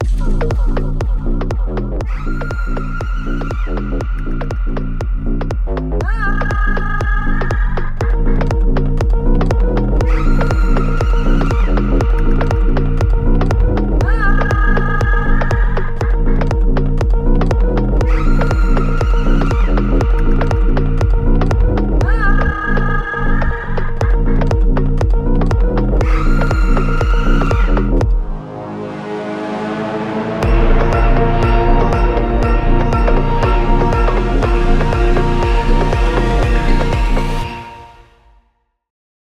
without dialogues, SFX and unwanted sounds